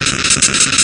Paralyze3.ogg